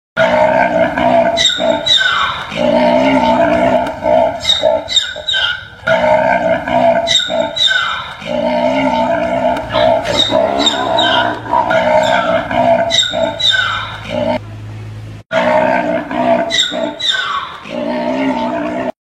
Sonneries » Sons - Effets Sonores » bruitage zebre